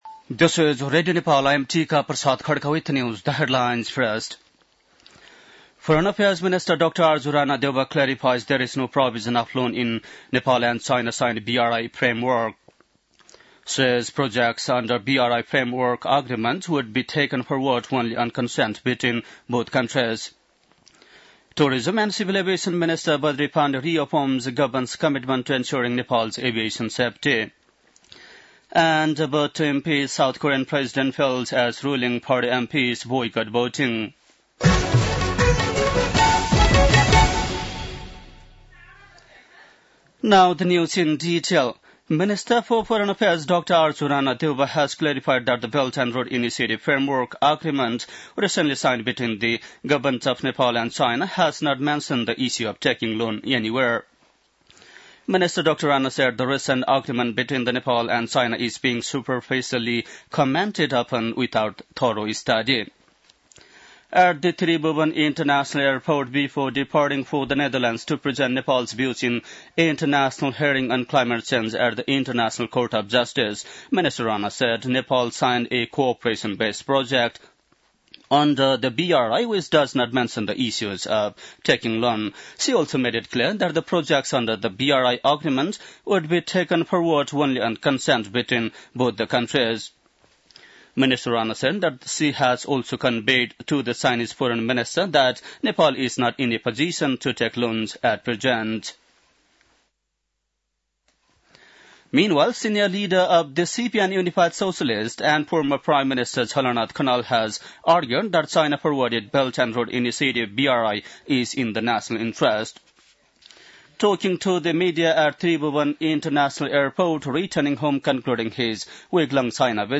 बेलुकी ८ बजेको अङ्ग्रेजी समाचार : २३ मंसिर , २०८१
8-PM-English-News-8-22.mp3